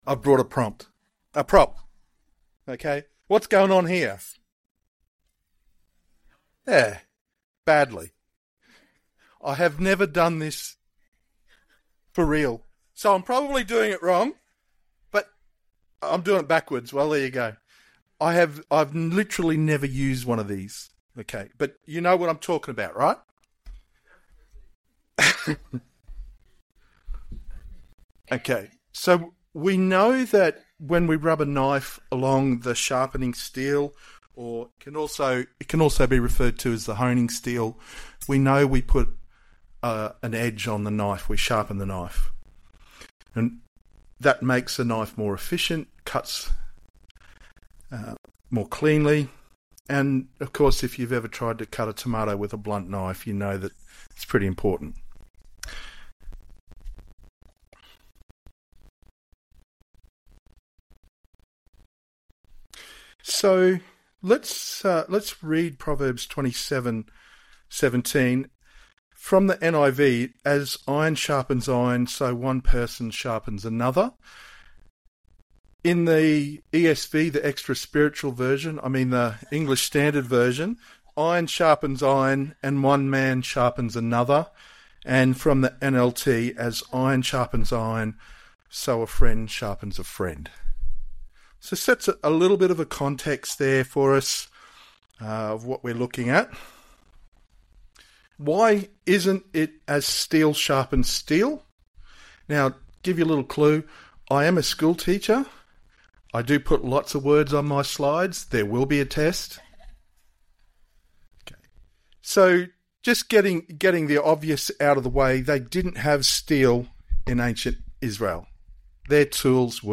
One-Off Sermons